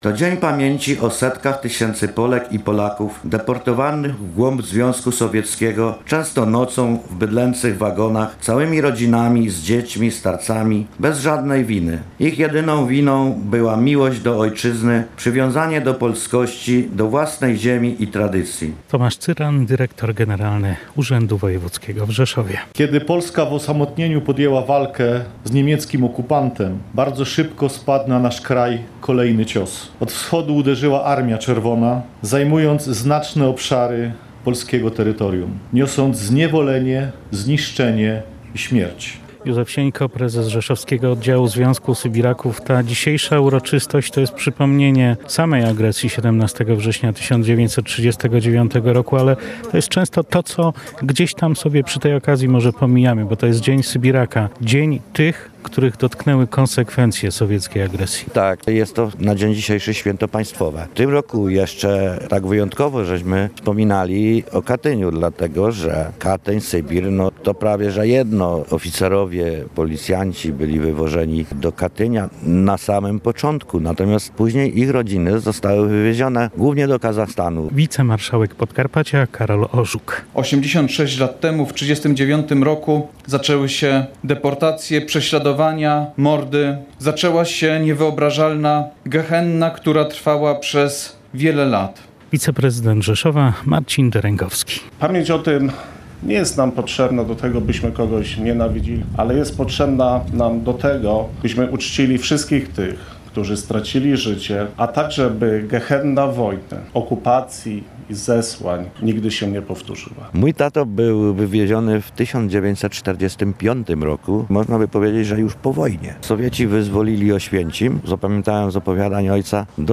Wojewódzkie obchody uroczystości 86. rocznicy agresji sowieckiej na Polskę w 1939 roku odbyły się przed południem pod pomnikiem Sybiraków w stolicy Podkarpacia.
Rzeszowskie obchody zakończyły Apel Pamięci, Salwa Honorowa i złożenie pod pomnikiem Sybiraka wieńców i wiązanek kwiatów.